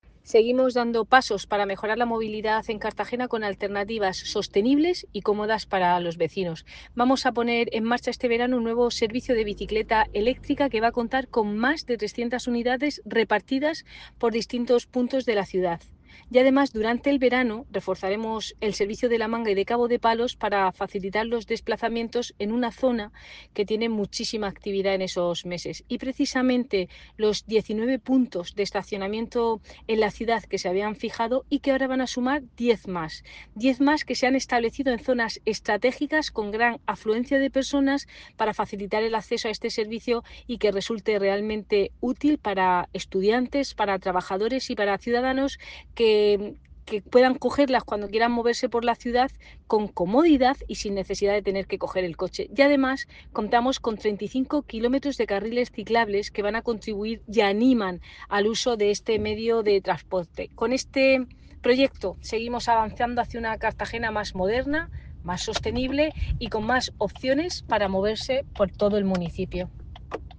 Enlace a Declaraciones de la concejal Cristina Mora sobre le nuevo servicio de bicicletas eléctricas